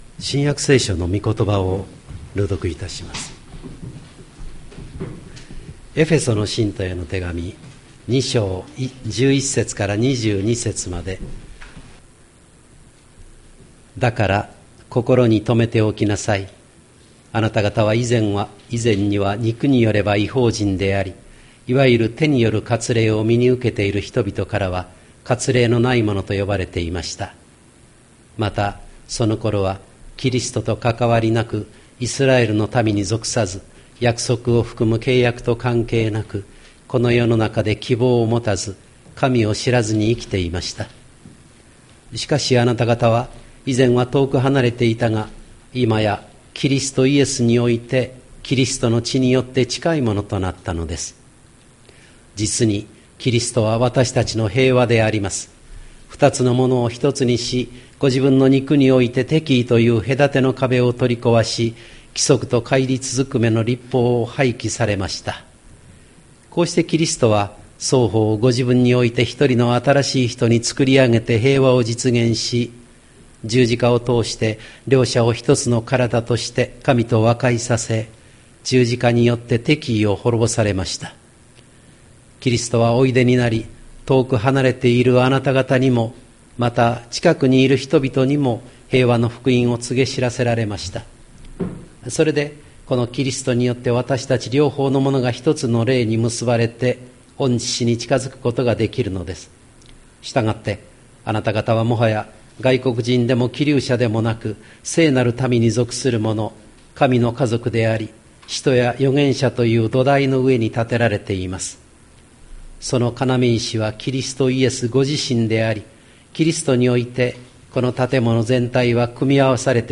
2023年08月27日朝の礼拝「礼拝と説教」吹田市千里山のキリスト教会
千里山教会 2023年08月27日の礼拝メッセージ。